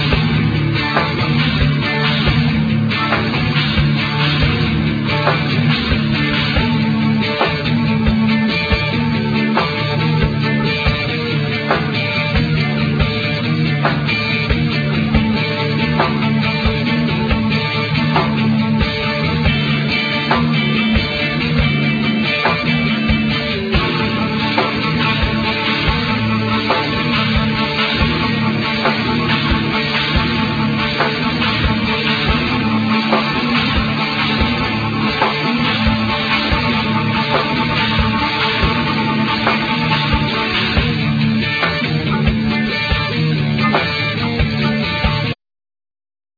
Vocal,Violin,Guitar
Cello
Guitar,Bass guitar,Tambourine
Drum,Bass guitar